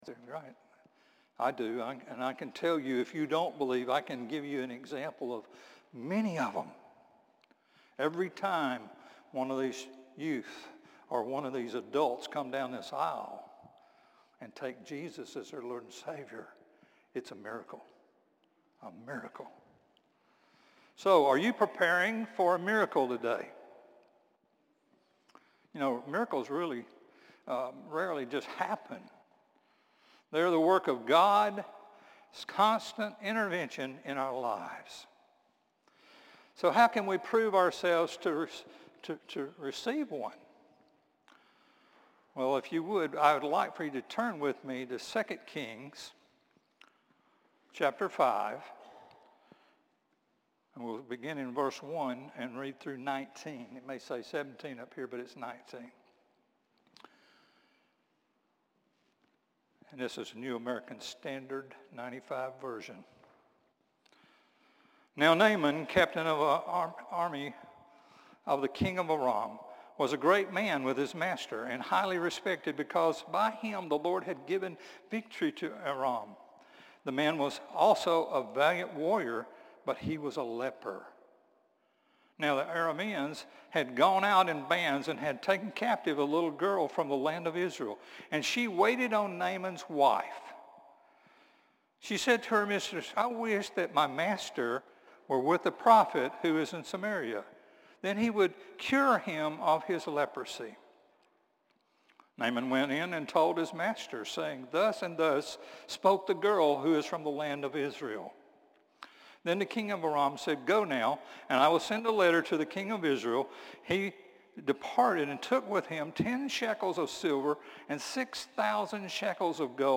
Sermons - Concord Baptist Church
Morning-Service-7-28-24.mp3